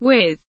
with kelimesinin anlamı, resimli anlatımı ve sesli okunuşu